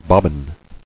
[bob·bin]